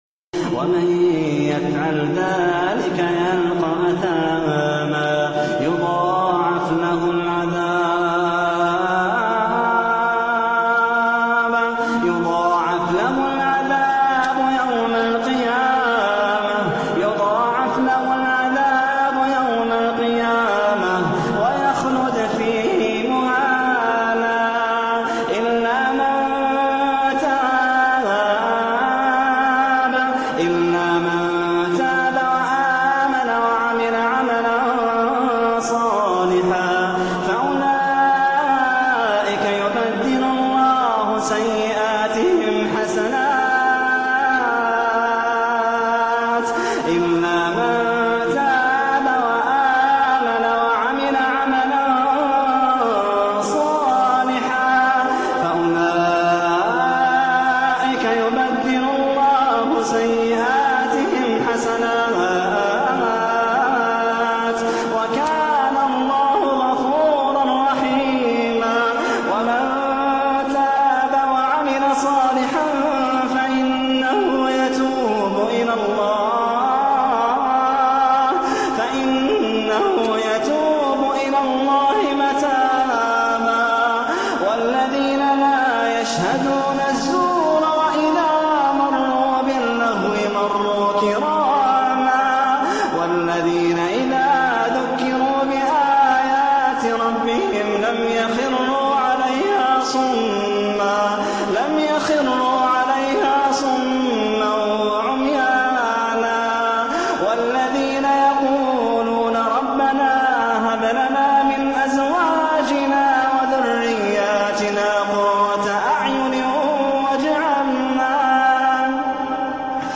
أواخر سورة ا(الفرقان) بتقنية| 8D بصوت الشيخ محمد اللحيدان❤